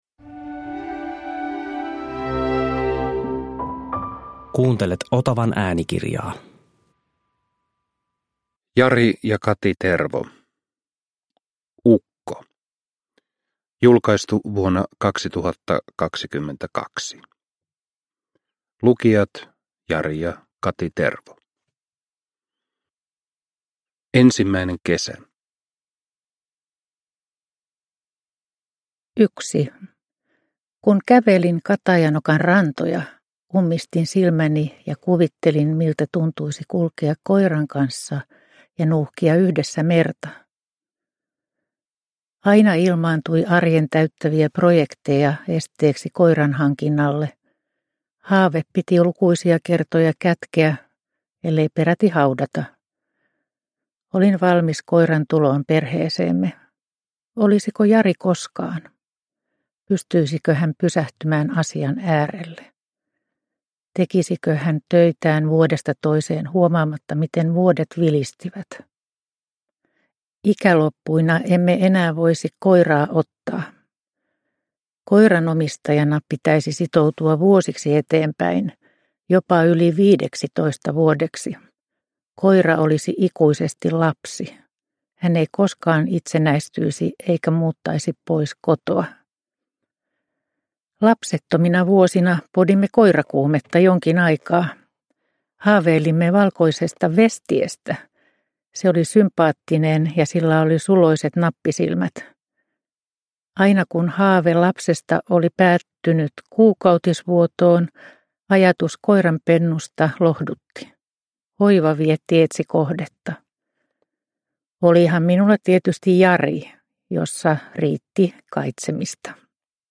Ukko – Ljudbok – Laddas ner